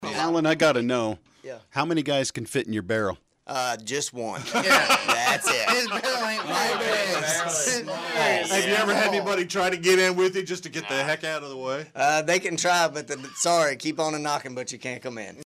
Three rodeo pros joined in the KSAL Morning News Extra with a look at life inside the ring and with an update from the Wild Bill Hickok Rodeo in Abilene.